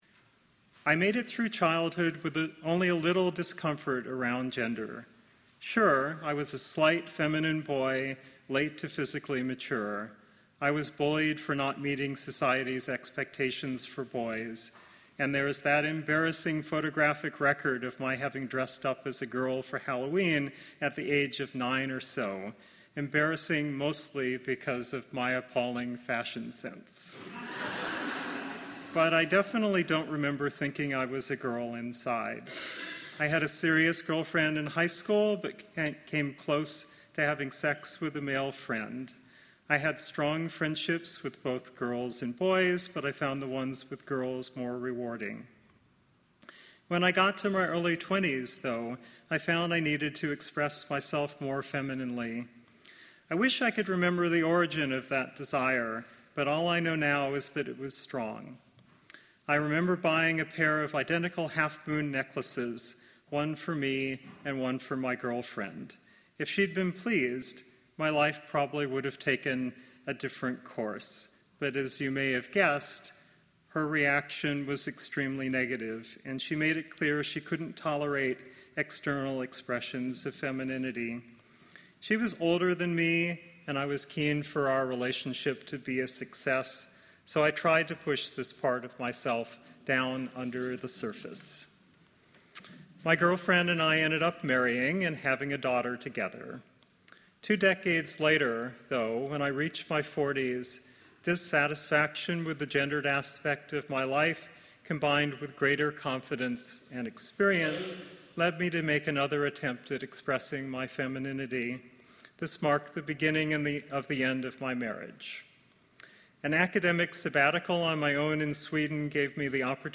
This service reflects upon transgender issues as part of our annual LGBTQ service, as we consider spirituality as a need for self-expression and recognition from the larger community.